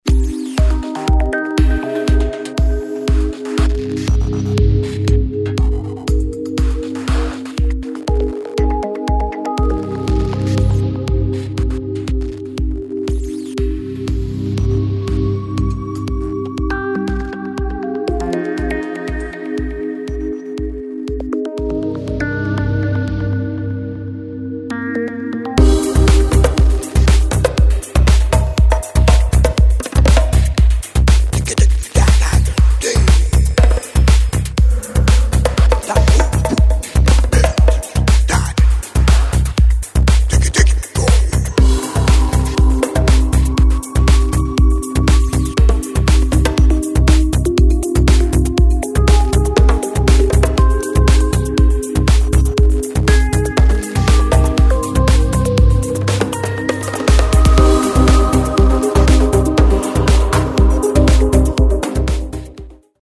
IDM/Electronica, Trance